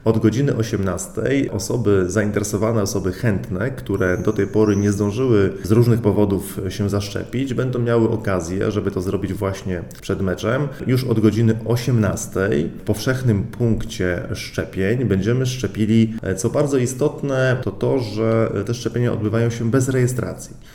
– Tego dnia będzie także okazja, aby móc się zaszczepić bez konieczności rejestracji szczepionką jednodawkową – dodaje burmistrz Lichtański: